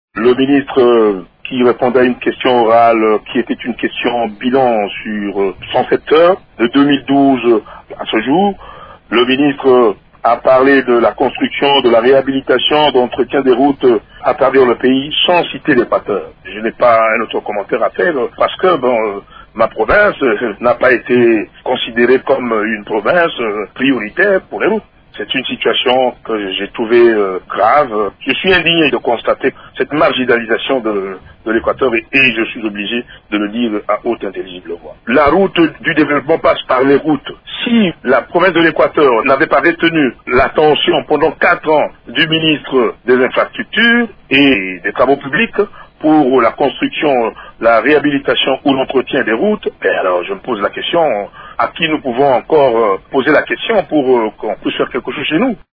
Vous pouvez écouter les explications du député Lokondo.